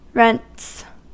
Uttal
Alternativa stavningar 'rents Uttal US Ordet hittades på dessa språk: engelska Ingen översättning hittades i den valda målspråket.